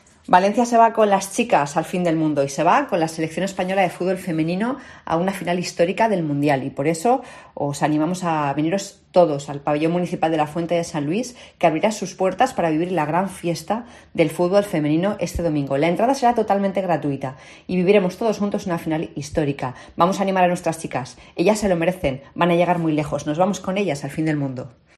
Declaraciones María José Catalá sobre la retransmisión de la Final de España Femenina en la Fonteta